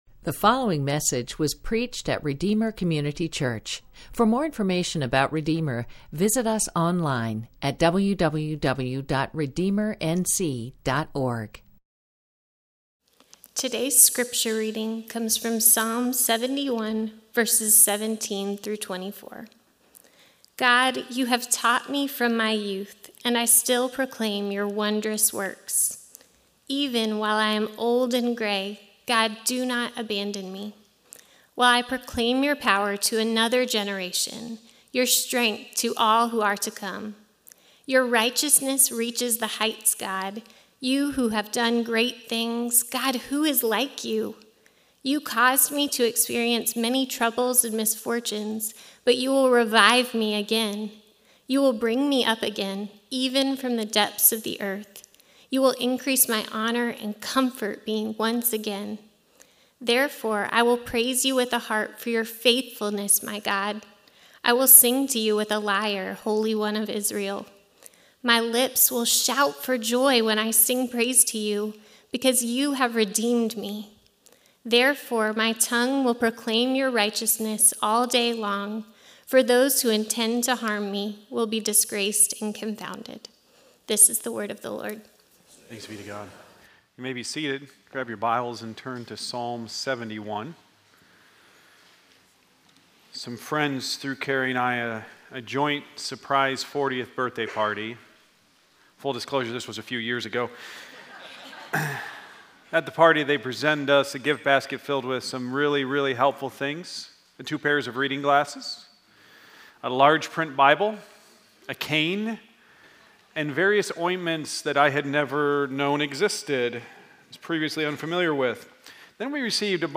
Sermons - Redeemer Community Church